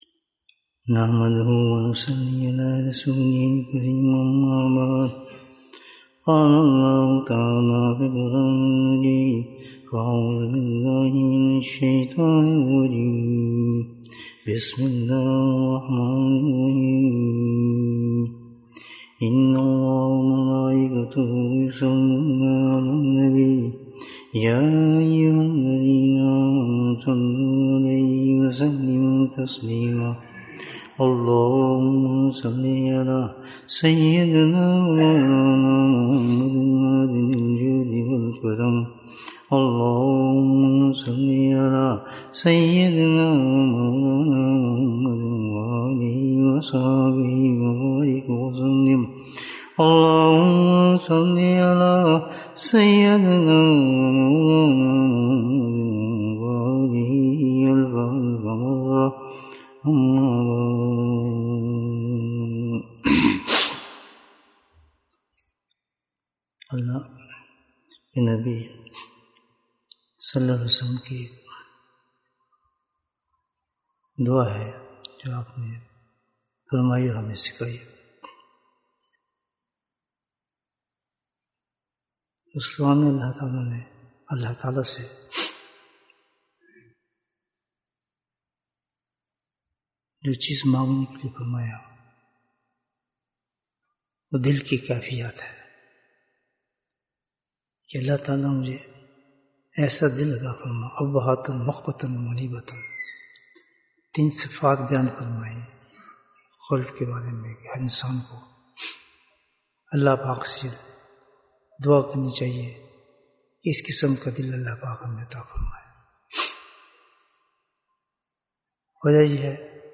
قربِ الہی کا راستہ Bayan, 34 minutes15th June, 2023